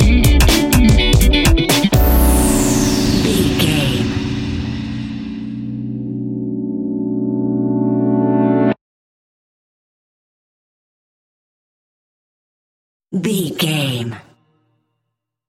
Aeolian/Minor
groovy
uplifting
futuristic
driving
energetic
happy
bass guitar
synthesiser
electric guitar
drums
piano
disco
nu disco
upbeat